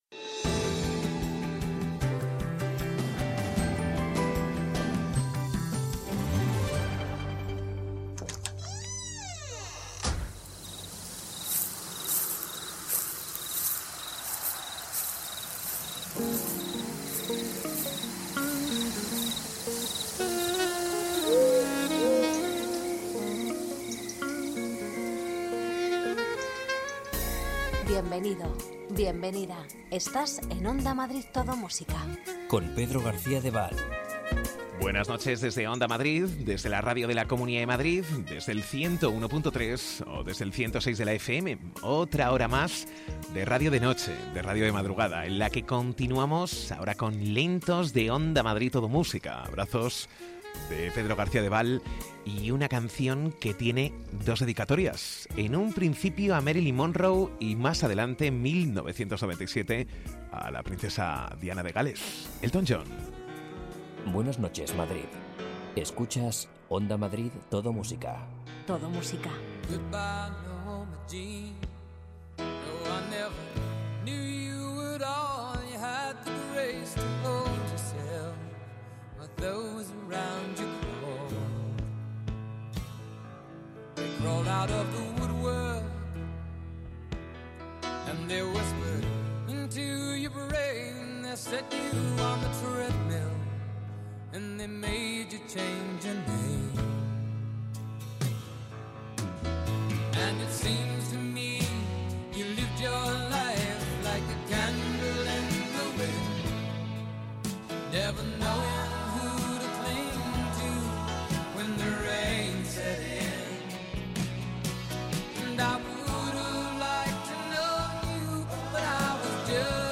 Ritmo tranquilo, sosegado, sin prisas... Las canciones que formaron parte de la banda sonora de tu vida tanto nacionales como internacionales las rescatamos del pasado durante la madrugada.